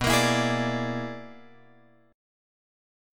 B Minor Major 13th